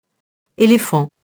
éléphant [elefɑ̃]